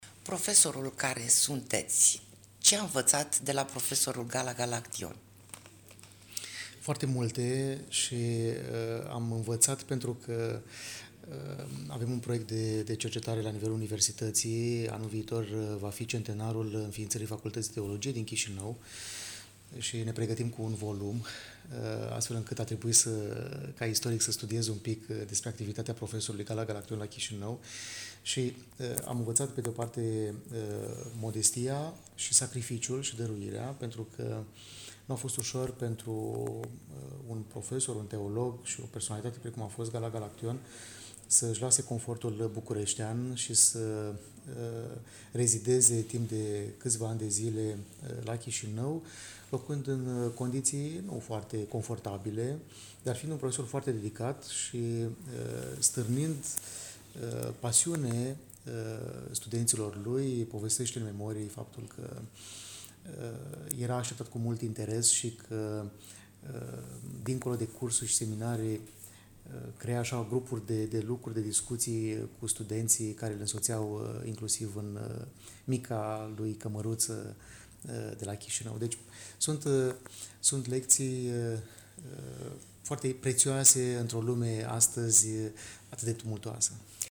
Universitatea „Alexandru Ioan Cuza” din Iași (UAIC) a organizat miercuri, 16 aprilie 2025, de la ora 12.00, în Mansarda Muzeului UAIC, conferința „Gala Galaction – profesor la Universitatea din Iași (1926-1940)”.